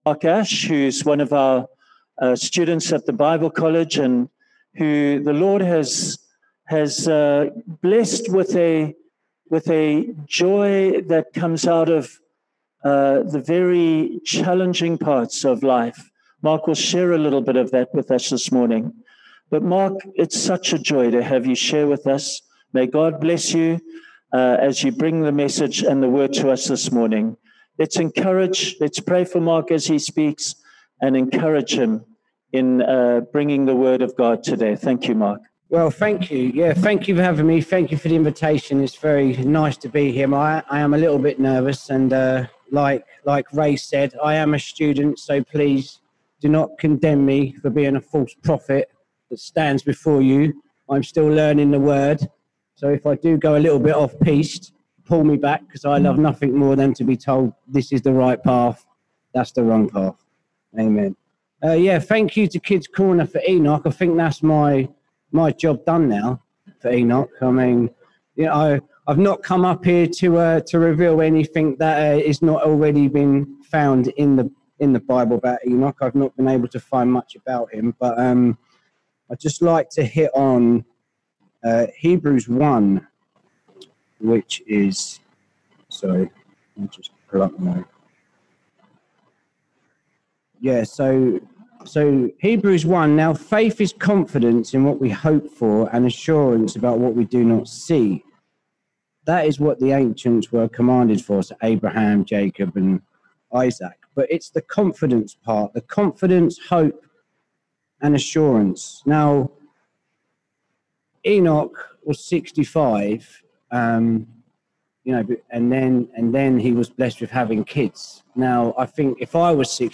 With a relaxing of the lock down rules during the Coronavirus crisis the church is able to run services again in the sanctuary, but with a limited number of worshippers.
Below is the recording of the sermon for this week.